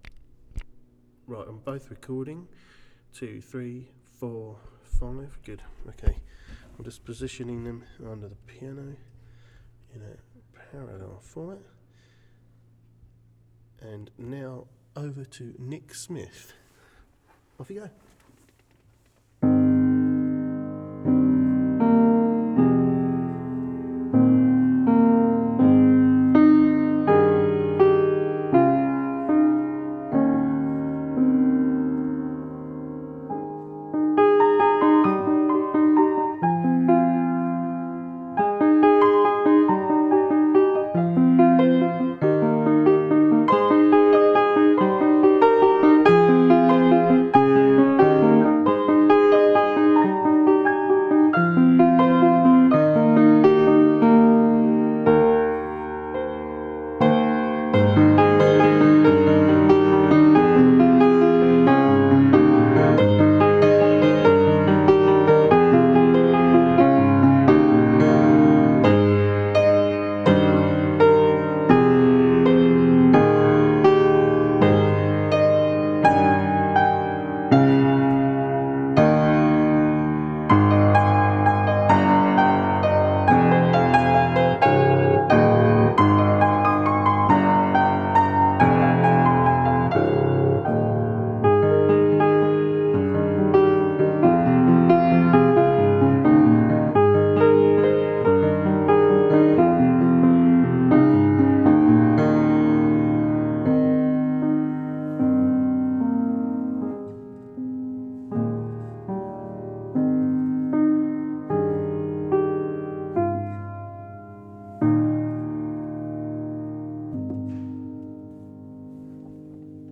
The following audio files were recorded on Olympus LS-10 and LS-11 devices. These are the original recordings, uncompressed (except for the mp3 files) and unedited to allow you to hear the quality of a recording made on the machine using the built-in microphones.
Piano Recording (6m57s)
WAV File - 16 bit Stereo 44kHz
olympusls11_piano.wav